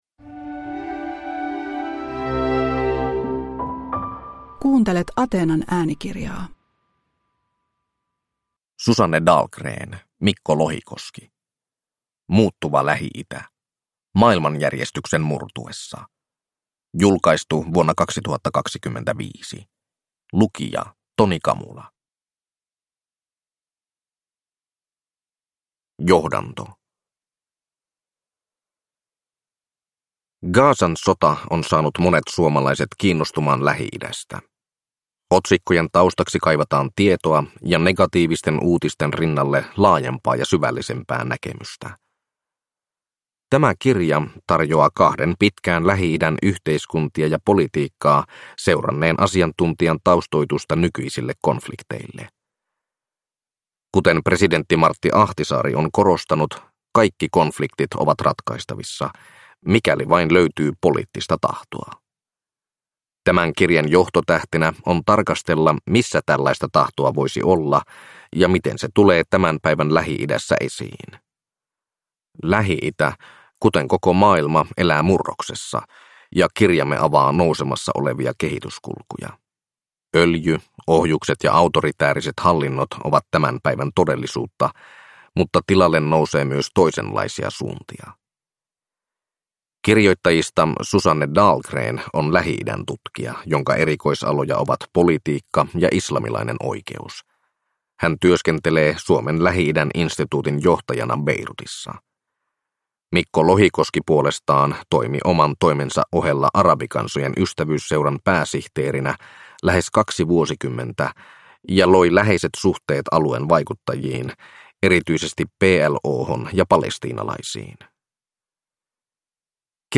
Muuttuva Lähi-itä – Ljudbok